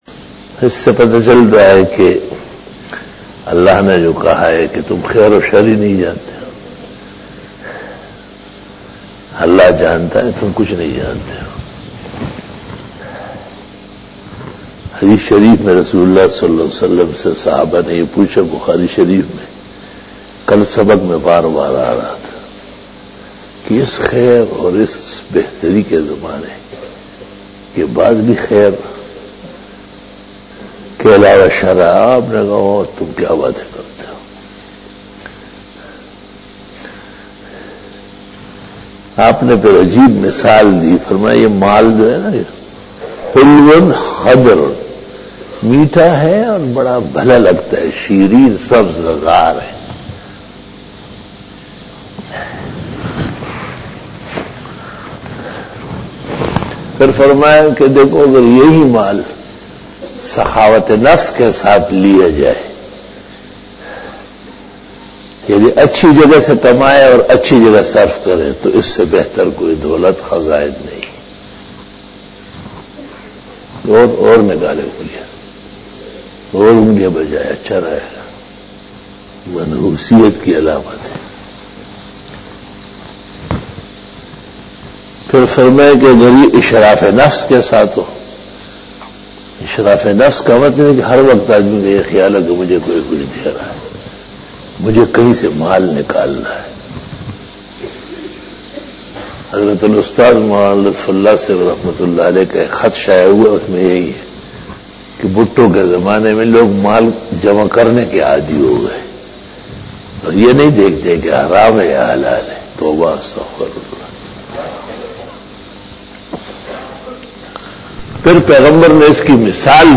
After Namaz Bayan
After Fajar Byan